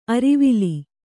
♪ arivili